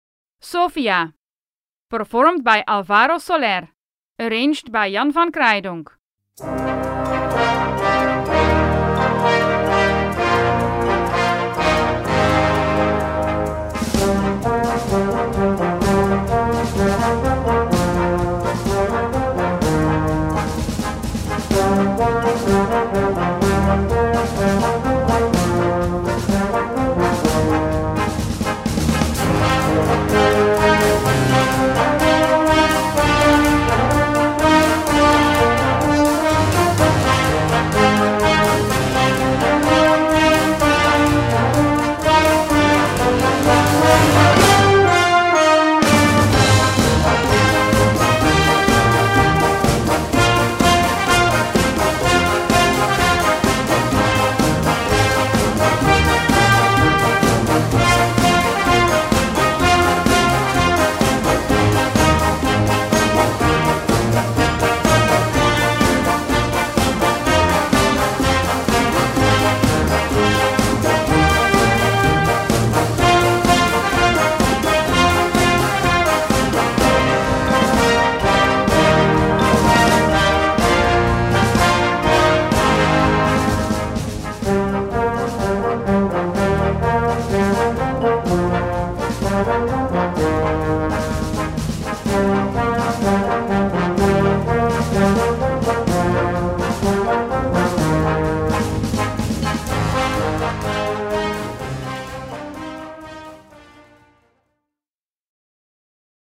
Gattung: Moderner Einzeltitel
Besetzung: Blasorchester
in einem Arrangement für Blasorchester.